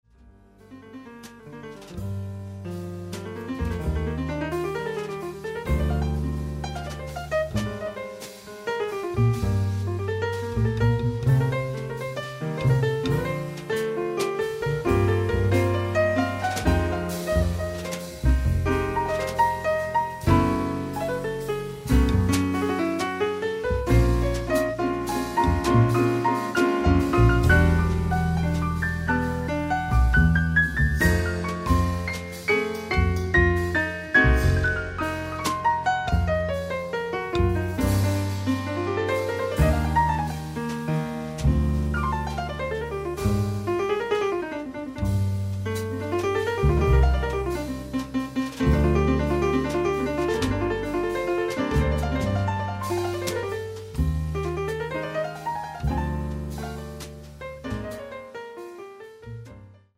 pianoforte
sassofono tenore
contrabbasso
batteria